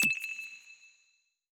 generic-hover.wav